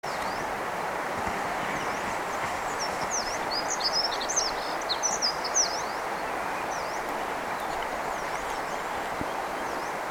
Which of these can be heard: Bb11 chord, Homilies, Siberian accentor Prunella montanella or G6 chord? Siberian accentor Prunella montanella